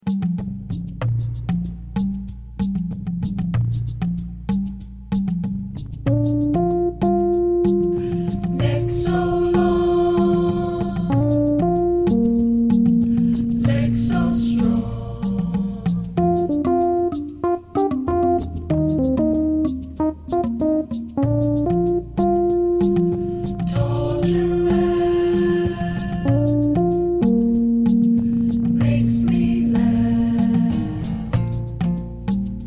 Instrumental Version (which has the harmony vocal included)